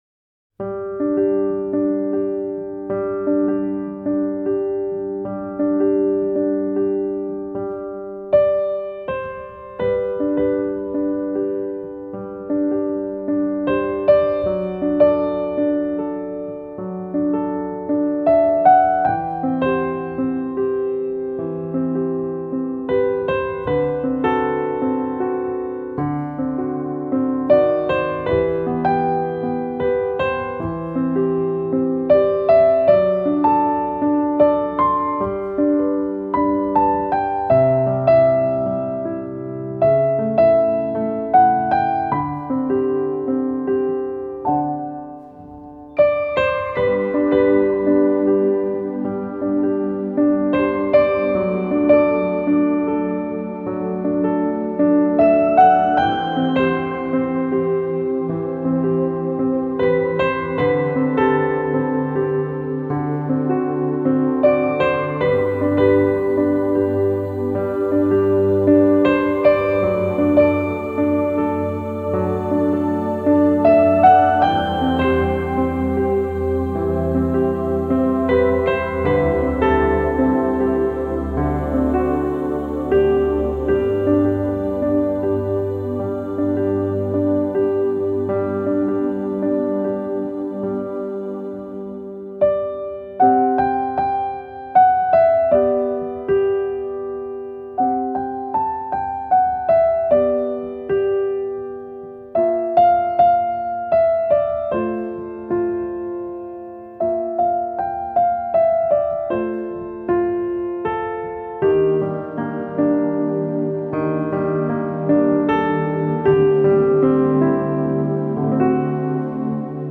专辑风格：Musical
钢琴
吉他
鼓、打击乐器
贝斯
长笛、高音萨克斯风
陶笛